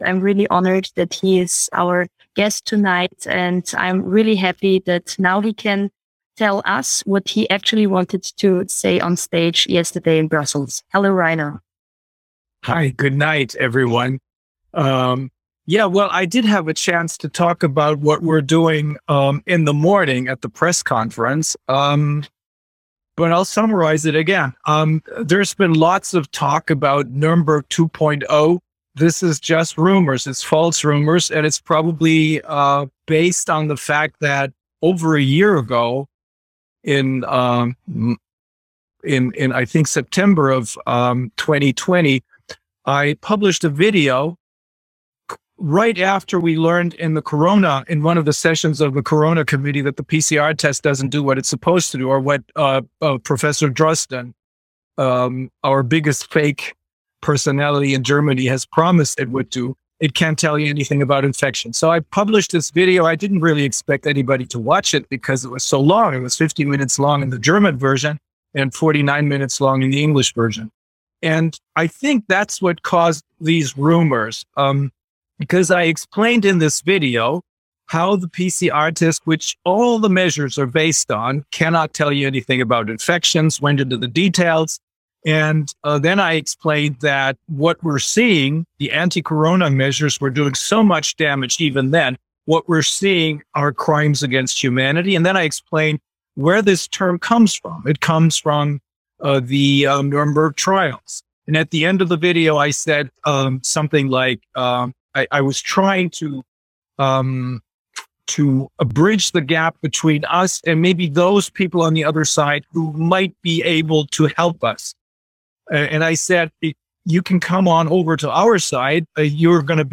Brussels Update & Covid Crimes Grand Jury Proceeding World Council For Health General Assembly Meeting, 24 January 2022 video